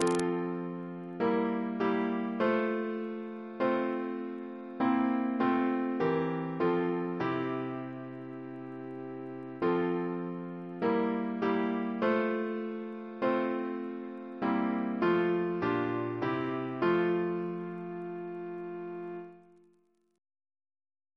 Double chant in F Composer: Richard P. Goodenough (1775-1826) Reference psalters: OCB: 302; PP/SNCB: 139